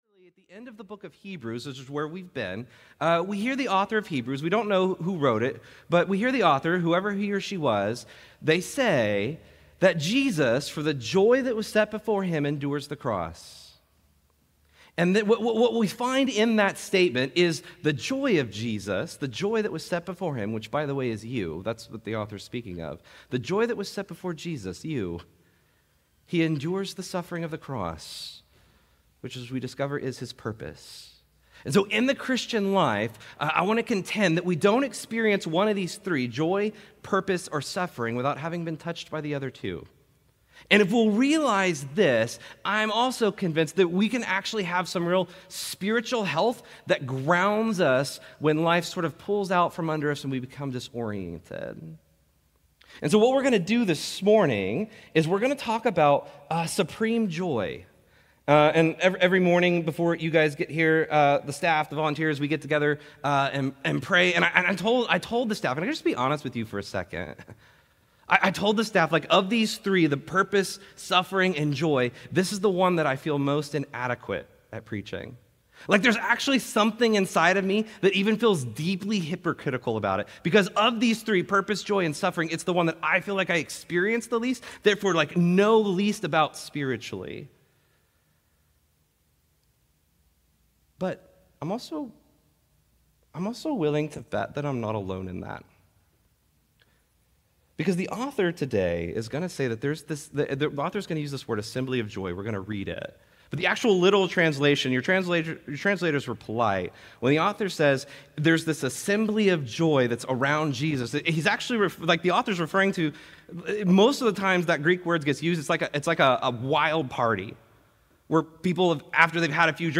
Restore Houston Church Sermons Is True Happiness Even Possible Jun 23 2025 | 00:38:30 1x 00:00 / 00:38:30 Subscribe Share Apple Podcasts Overcast RSS Feed Share Link Embed